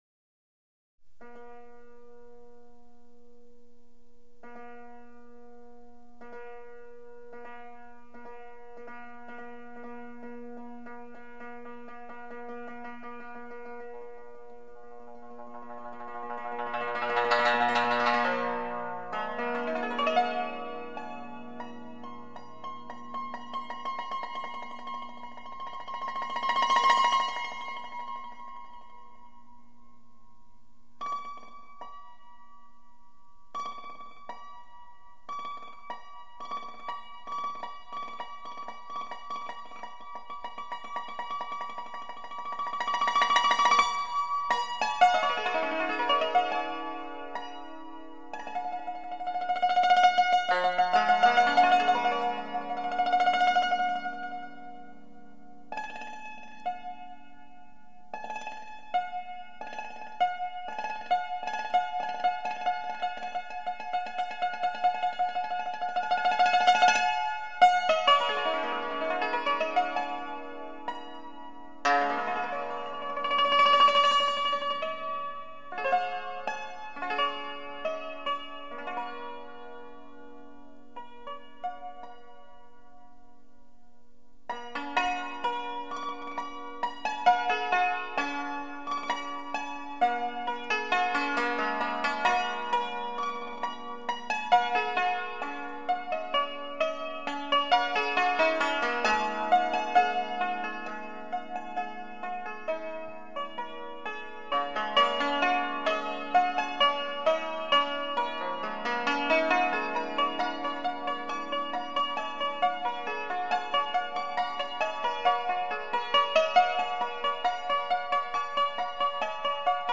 乐曲将主题进行 多种手法的变奏，在情绪和节奏上也有着强烈的对比，随着音符的流动，使人进入诗情画意中。